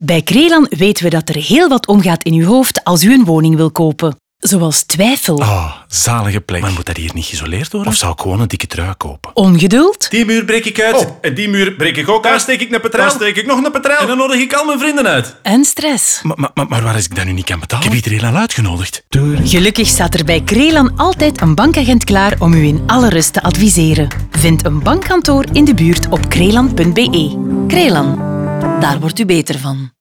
Zeker in radio werkt dit goed met een innerlijk stemmetje dat ons meeneemt langs al die gevoelens, variërend van stress en twijfel tot euforie.
Crelan Radio Woonkrediet NL.wav